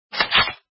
eqp_awm_switch.mp3